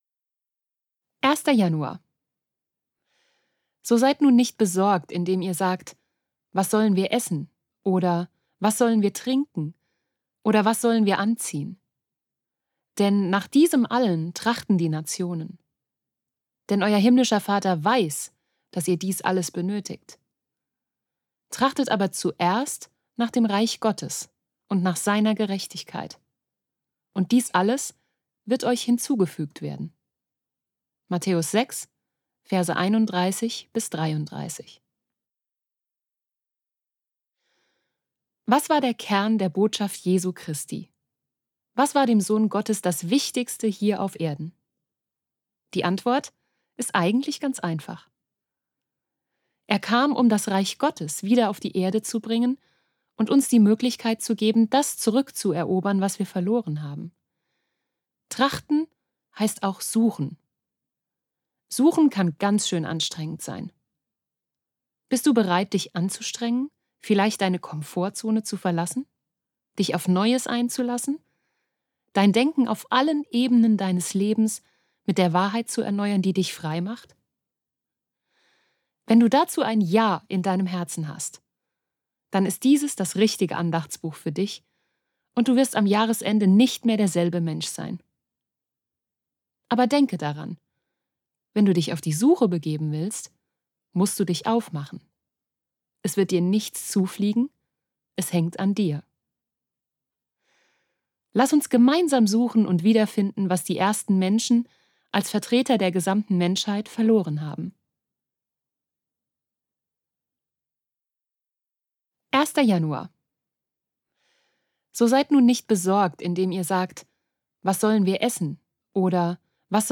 Gott hatte einen superguten Tag, als er dich schuf - Hörbuch